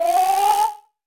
flyClose3.ogg